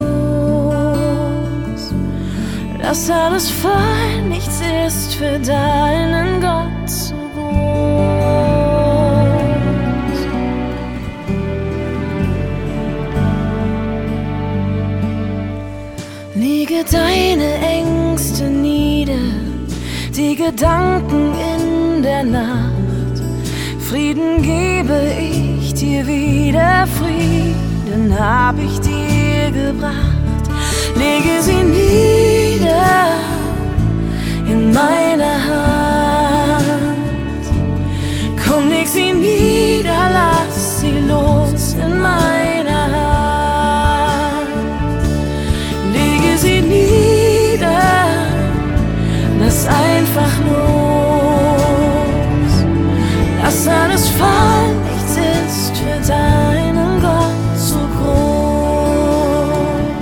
Worship 0,99 €